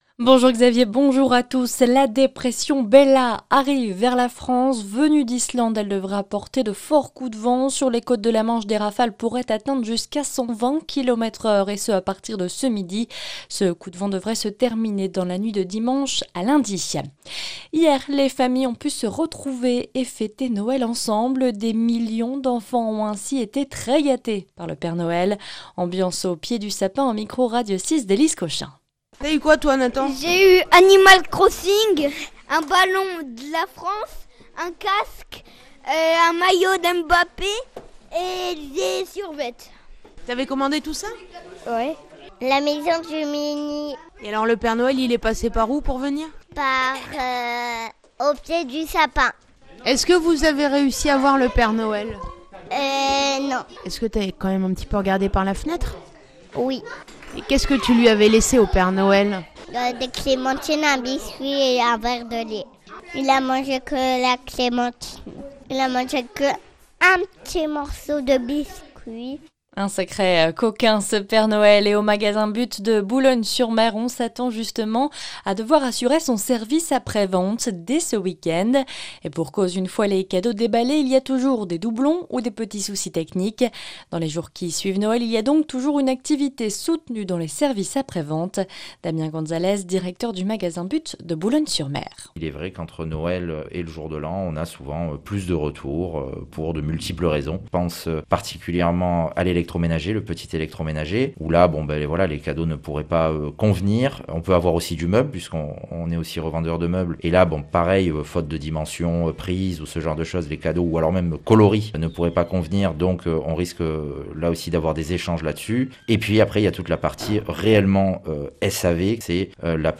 Le journal du samedi 26 décembre